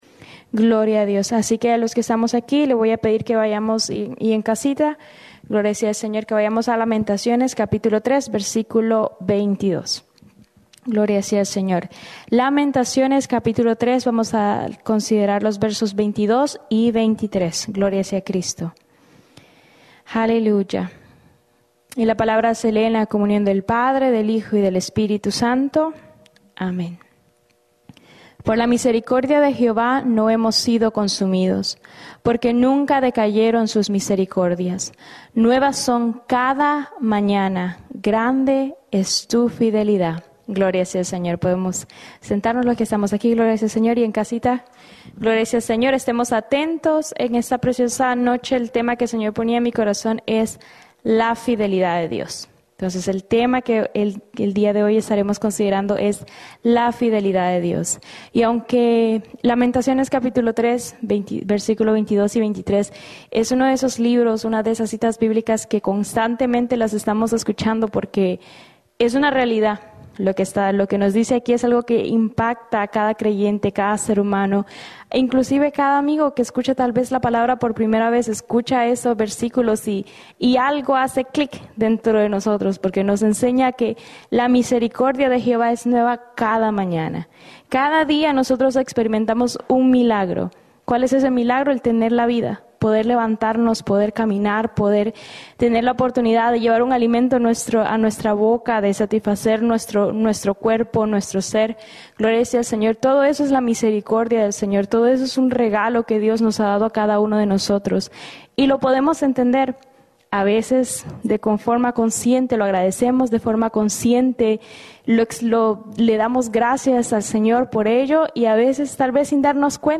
Predica: